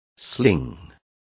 Complete with pronunciation of the translation of slinging.